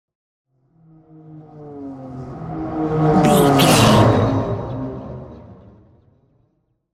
Pass by vehicle engine deep
Sound Effects
pass by
car
vehicle